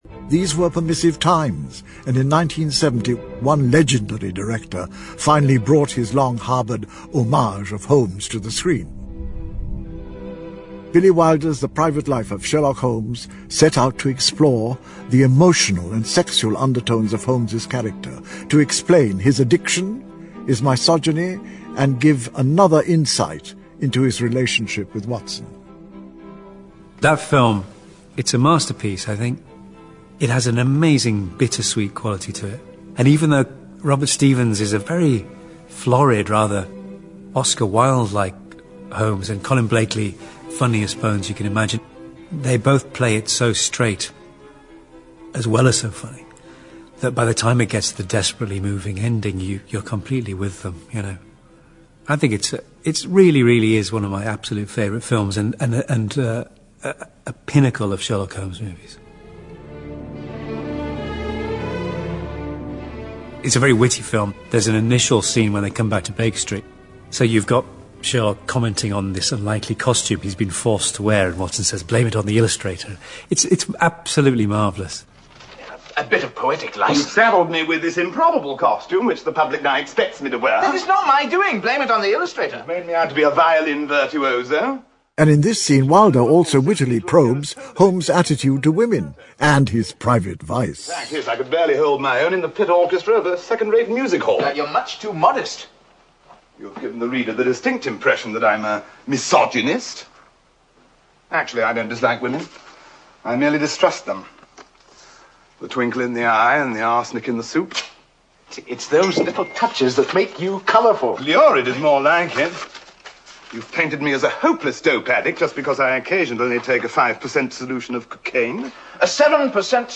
在线英语听力室如何成为福尔摩斯 第18期的听力文件下载, 《如何成为福尔摩斯》栏目收录了福尔摩斯的方法，通过地道纯正的英语发音，英语学习爱好者可以提高英语水平。